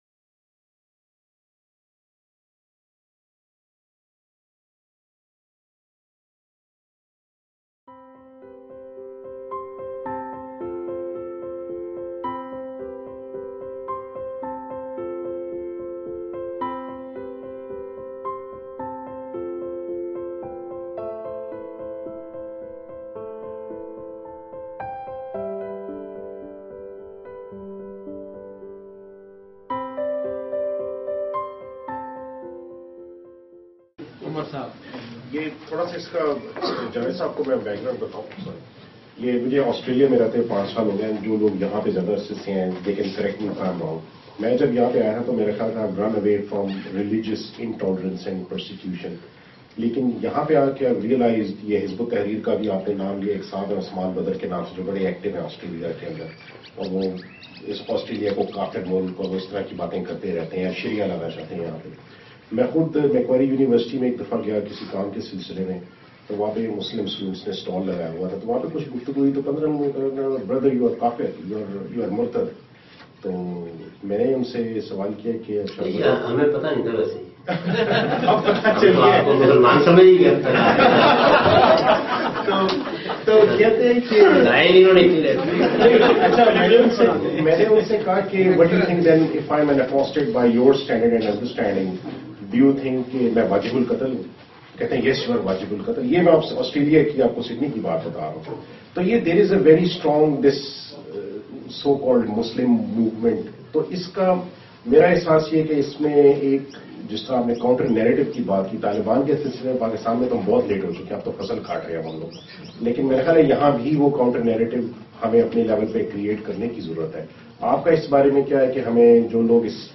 This series of lectures was recorded in Australia in January 2014.